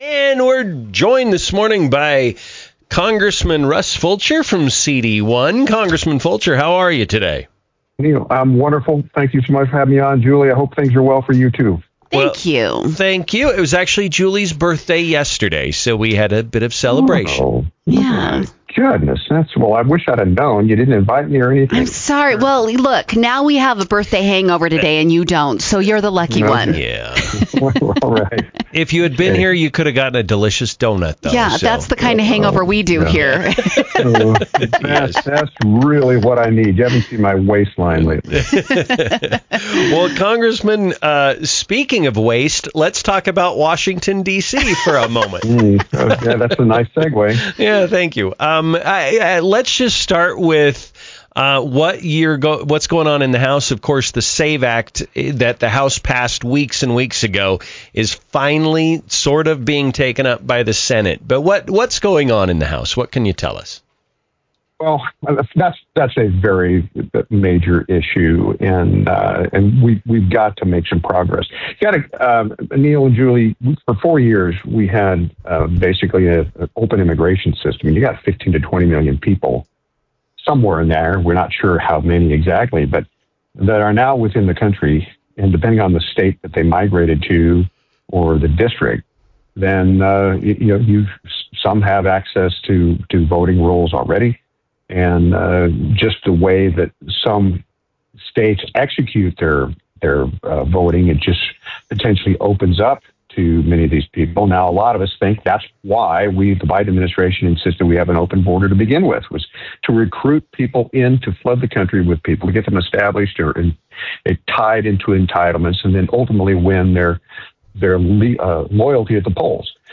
INTERVIEW: Rep. Russ Fulcher on Election Integrity, Tax Policy - Newstalk 107.9